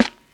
Boom-Bap Snare II.wav